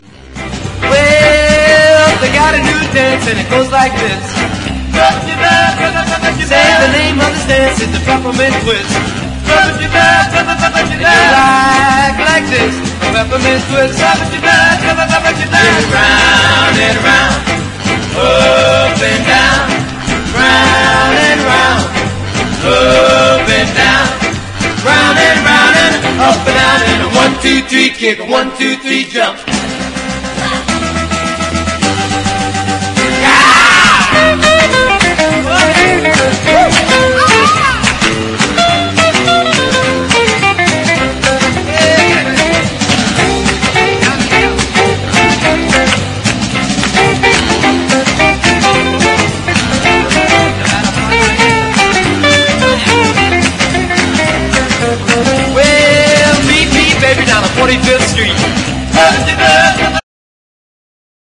多彩なゲストと朗らかな空気が魅力のエンターテインメント・アルバム。